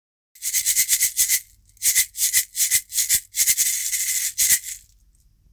カヤンバ極小柄つき
涼しげな音のマラカスです。
素材： 木 水草 石
仕入国： ケニア